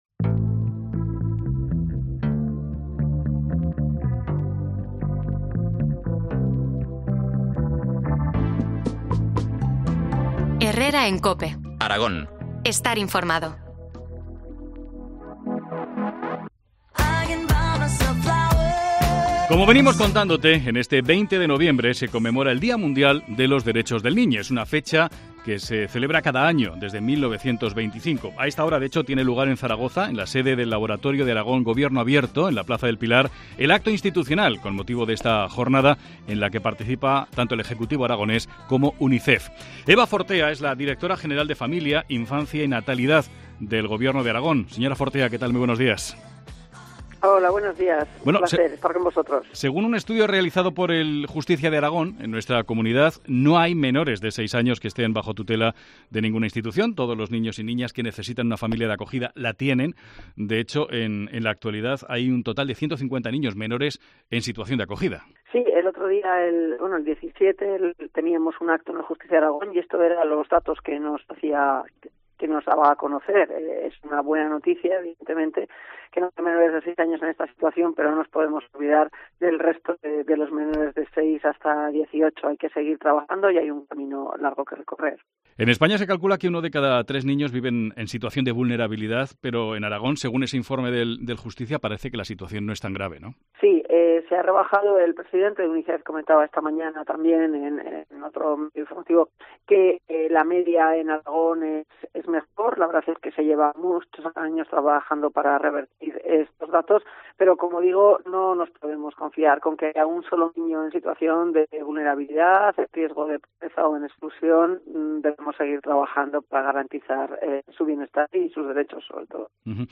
Entrevista a Eva Fortea, directora general de Infancia, sobre el Día de los Derechos del Niño.